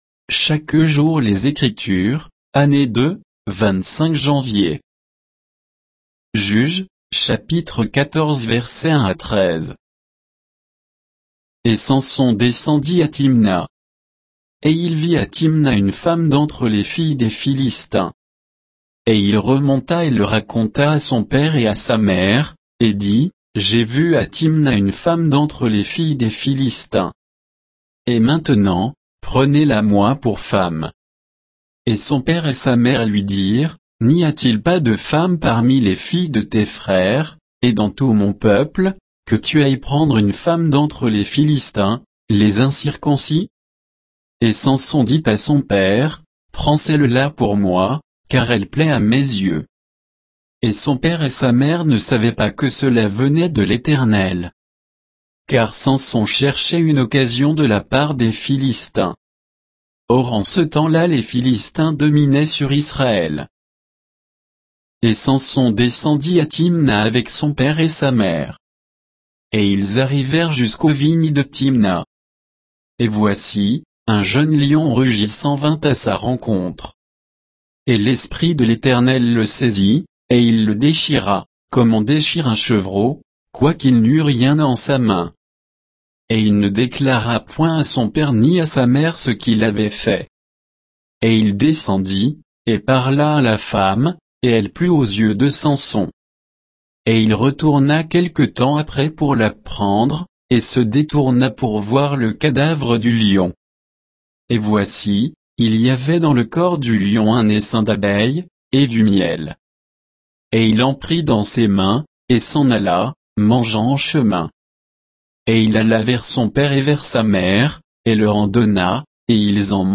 Méditation quoditienne de Chaque jour les Écritures sur Juges 14